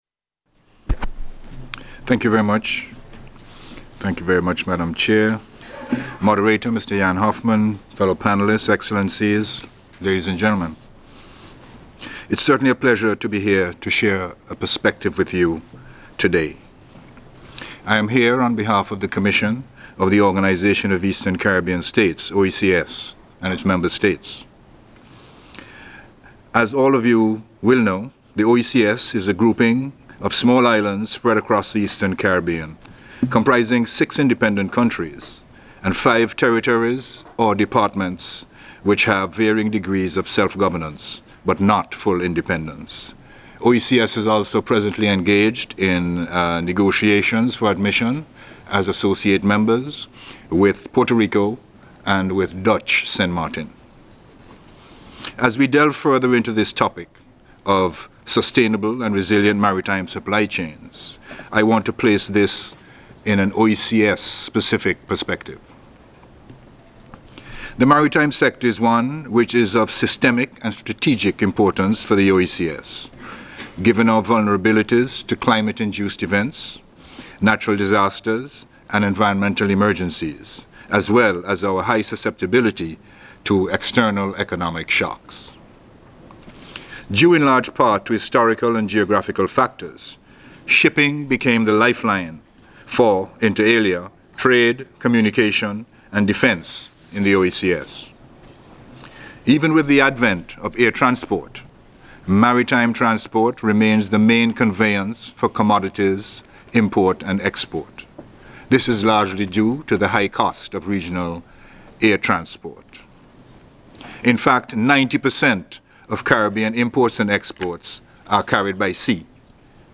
UNCTAD’s 4th Oceans Forum on trade-related aspects of Sustainable Development Goal 14 was held in Geneva, Switzerland from April 6 - 8, 2022 under the theme A shift to a sustainable ocean economy: Facilitating post-COVID-19 recovery and resilience.
Ambassador Murdoch at UNCTAD 4th Oceans Forums.mp3